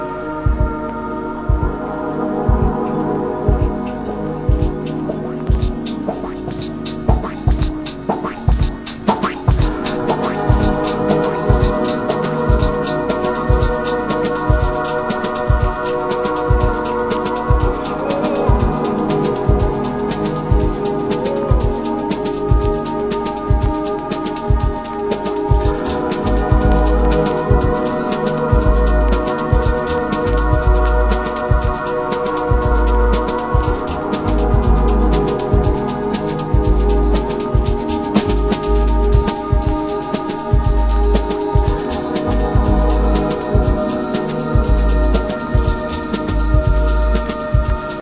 カリンバの音色が印象的な、エレクトリック・アフロ・ブレイク！